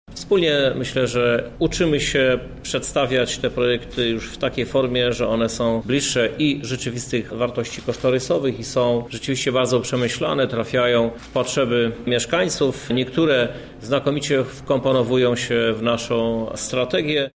Prezydent Krzysztof Żuk zaznaczył, że coraz większa liczba projektów jest dobrze przygotowana.